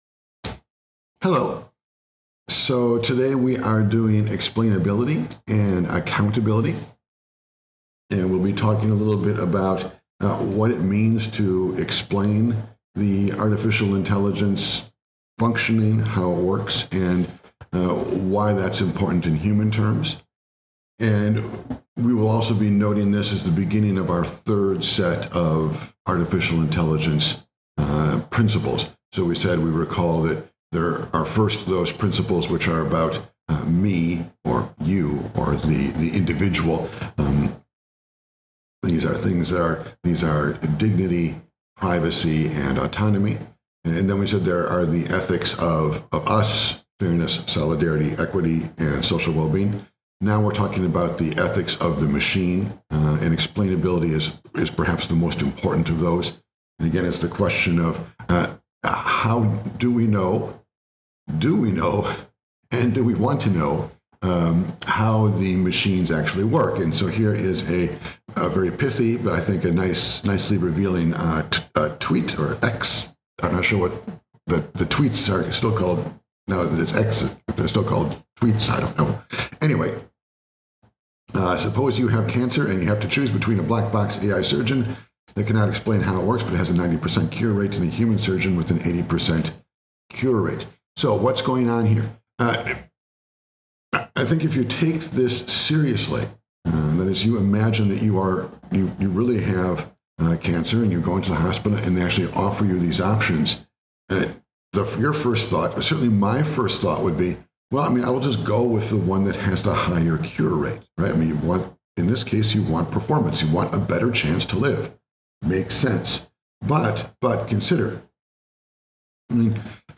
Lecture In this lecture we explore the AI ethics of Explainability / Accountability .
The lecture is meant to include images, but there is a pure audio version here that you may download.